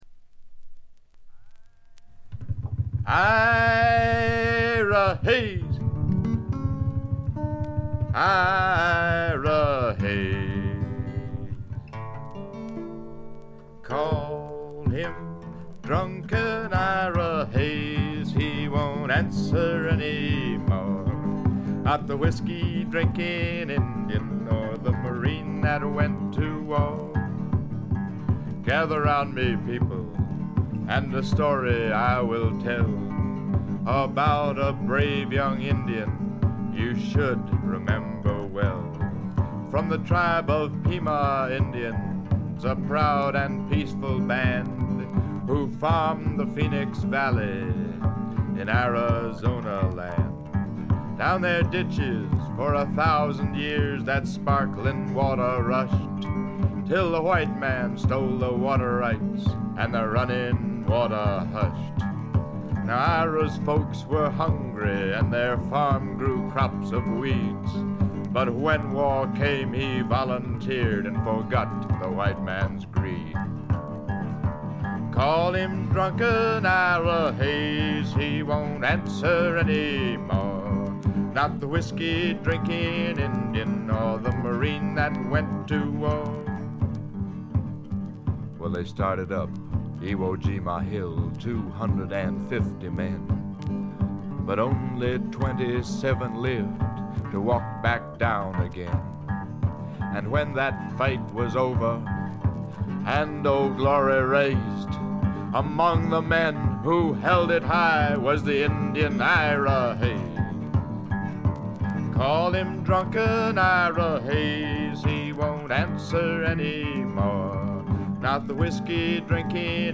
プレス起因と思われますが、ところどころでチリプチ。散発的なプツ音少し。
ジャケットからして異様な存在感を放っていますが、音の方も独特のノリがあって一種呪術的なすごい迫力です！
試聴曲は現品からの取り込み音源です。
Guitar, Vocals
Indian Drums